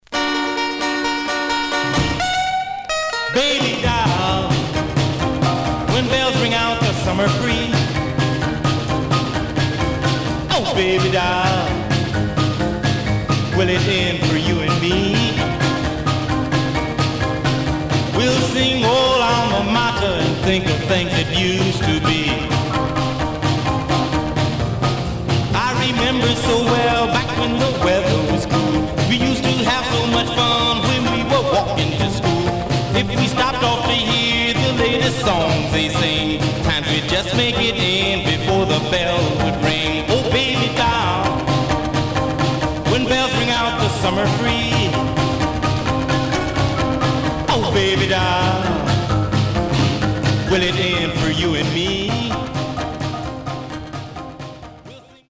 Stereo/mono Mono
Rythm and Blues